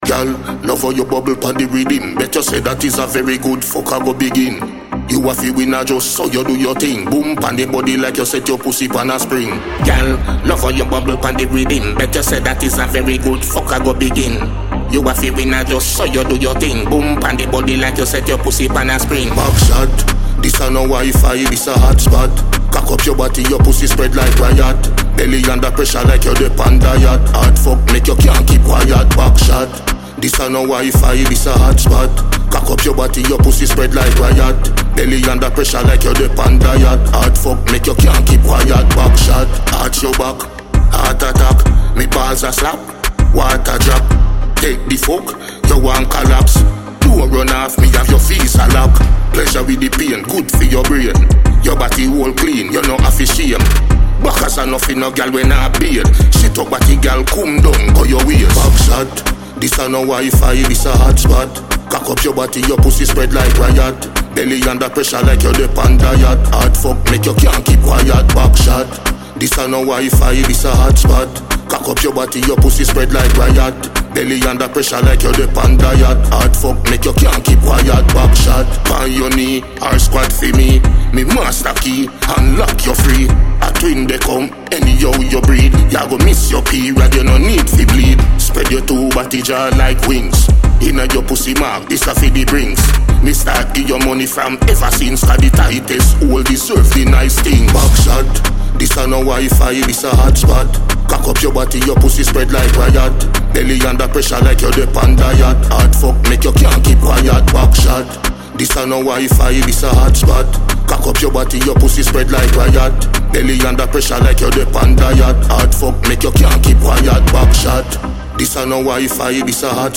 Multiple award-winning Jamaican dancehall musician